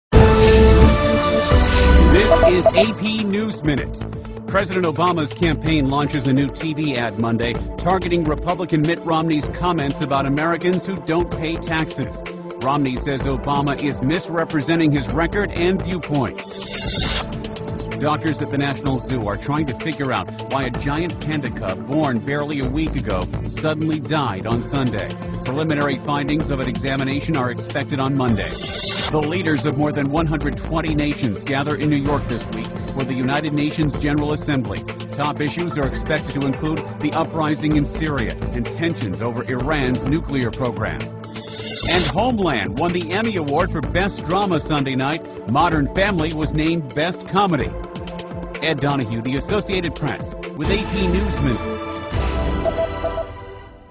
电视新闻片长一分钟，一般包括五个小段，简明扼要，语言规范，便于大家快速了解世界大事。